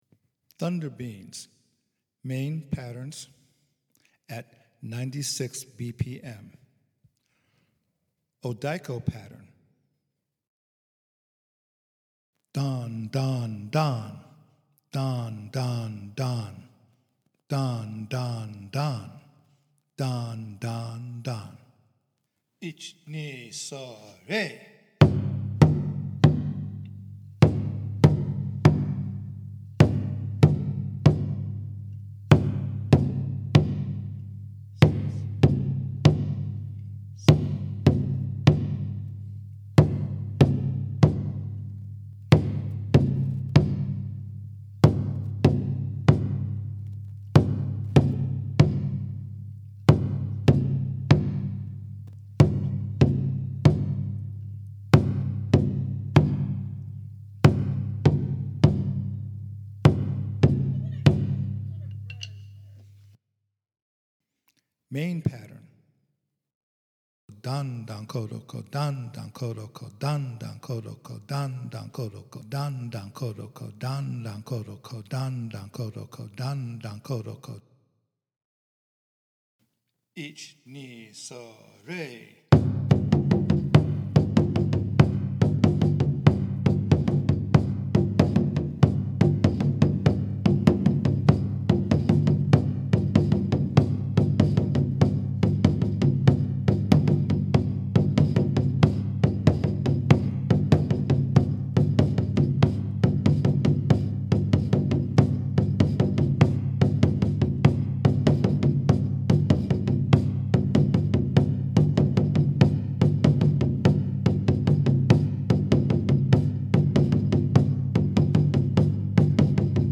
It is polyrhythmic and contains rhythmic subtiltes like interrruptions and triplets. It is also quite upbeat and energetic.
A recording of the Main Odiko & Chu patterns of Kaminari Tamashi in 96 BPM, separate, with kuchishoga lead-ins.